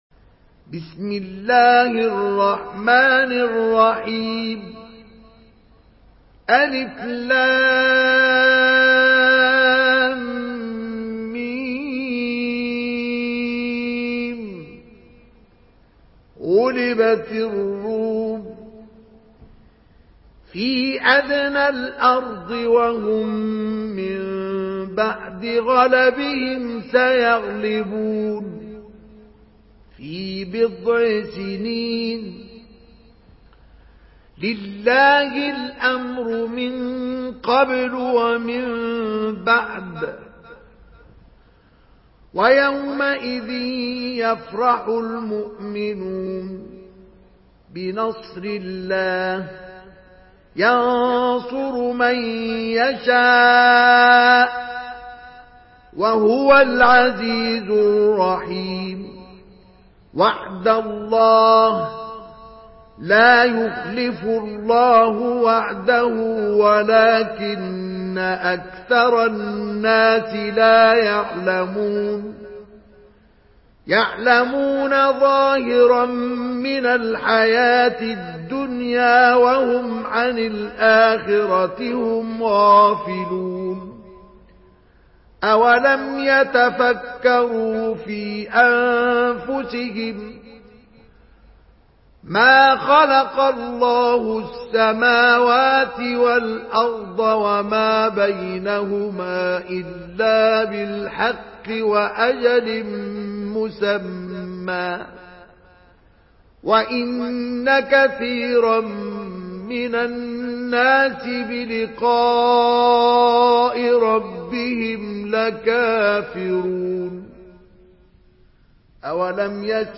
Surah আর-রূম MP3 in the Voice of Mustafa Ismail in Hafs Narration
Murattal Hafs An Asim